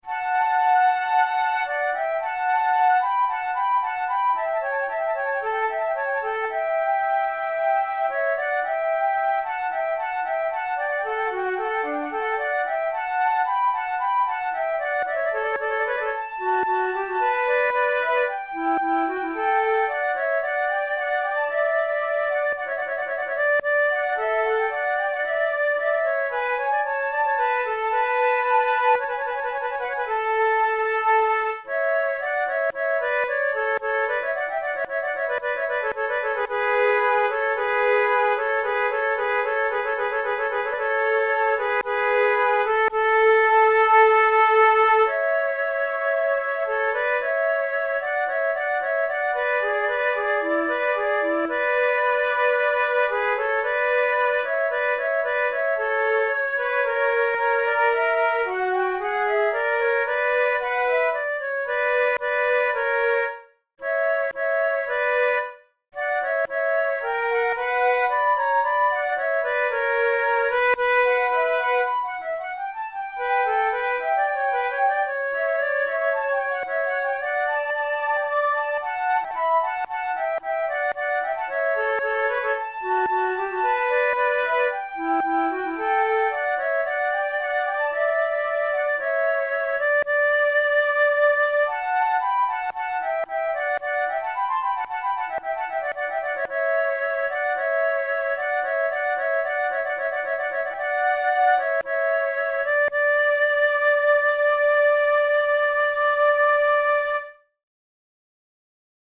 from Sonata for two flutes in D major
This Adagio is the opening movement of a Sonata in D major for two flutes by French Baroque composer Michel Blavet.
Categories: Baroque Sonatas Written for Flute Difficulty: intermediate